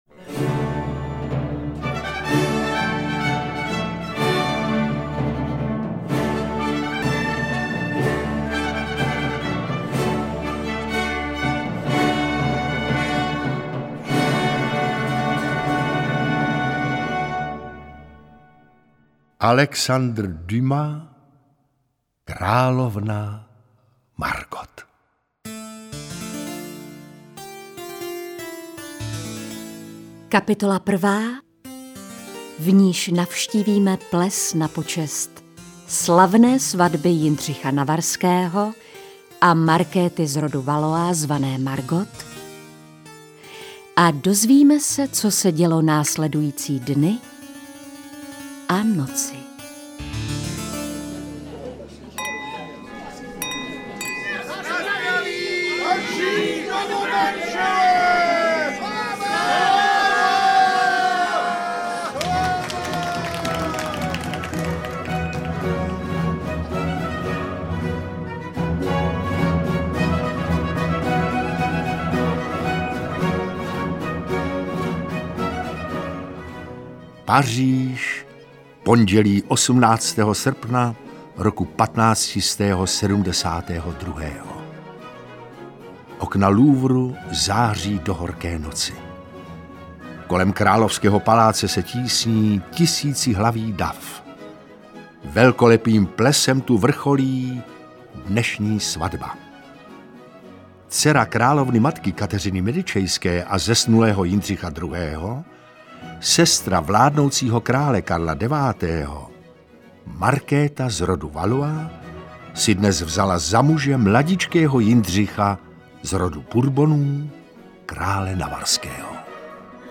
Interpreti:  Otakar Brousek ml., Lukáš Hlavica, Taťjána Medvecká, Petr Nárožný, Ilja Racek
Svědkem toho vraždění budete ve velkorysém stereo provedení. Stejně tak si vychutnáte lov na kance v režii krále Karla IX. Davy dvořanů vás ohluší při korunovaci.